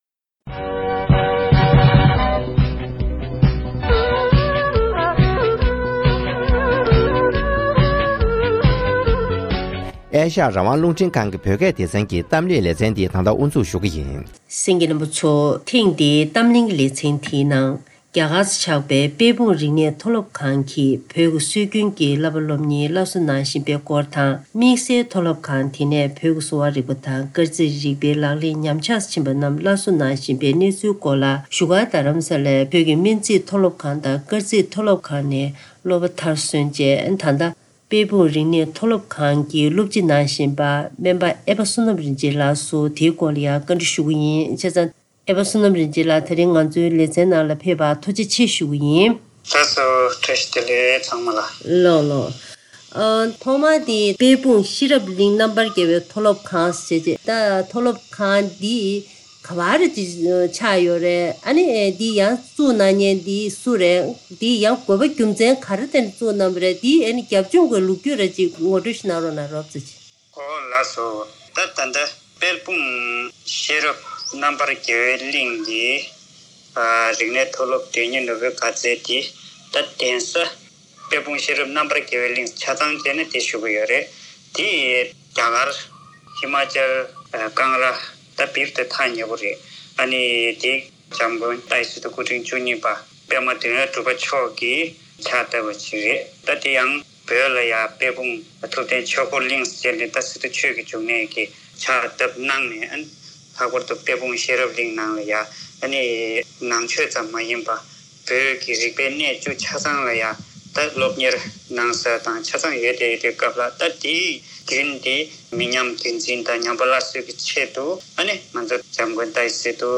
བཀའ་འདྲི་ཞུས་པ་ཞིག་སྙན་སྒྲོན་ཞུ་ཡི་ཡིན།